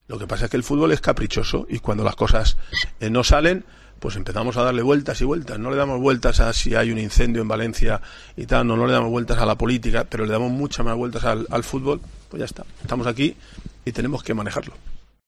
Rafa Benítez comparece en la previa del partido ante el Almería